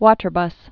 (wôtər-bŭs, wŏtər-)